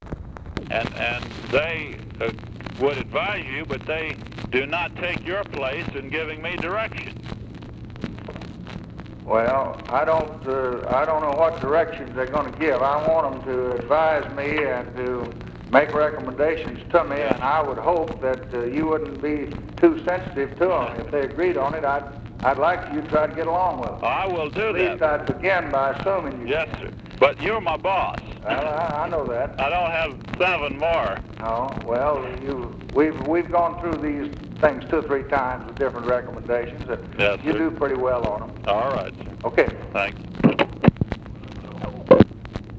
Conversation with NAJEEB HALABY, April 1, 1964
Secret White House Tapes